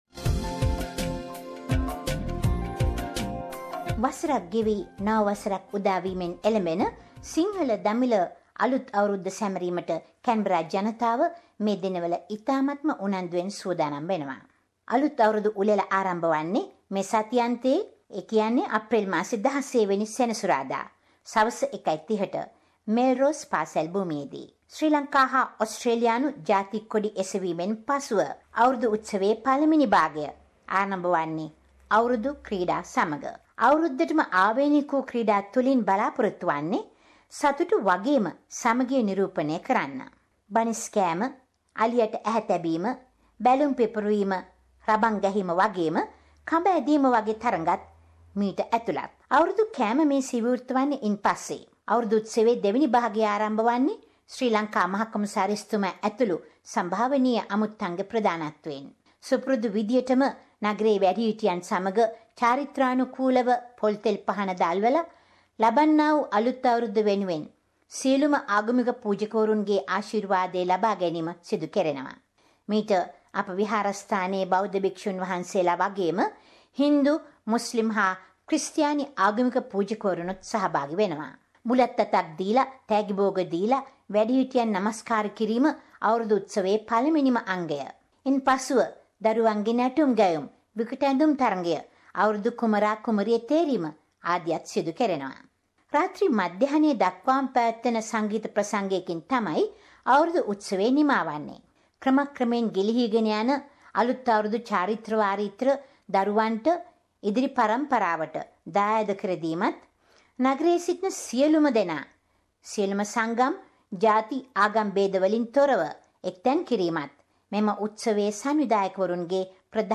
A report on Sinhala New Year celebrations in Canberra